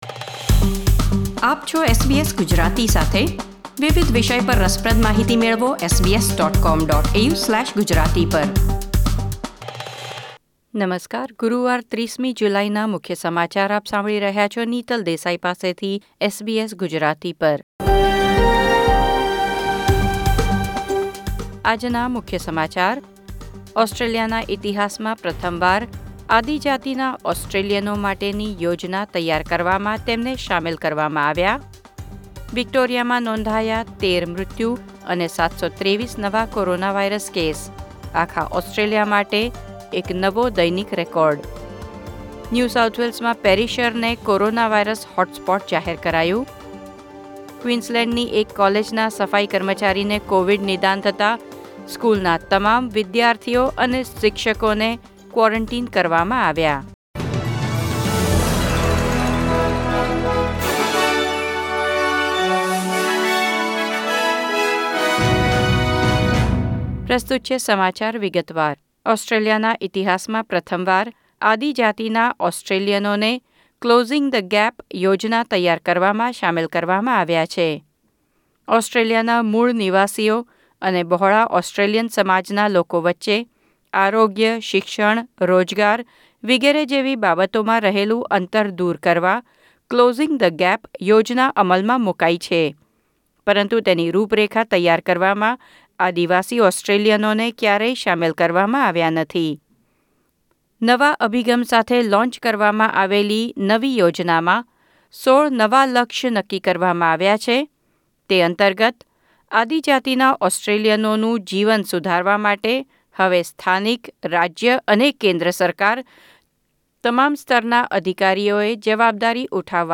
SBS Gujarati News Bulletin 30 July 2020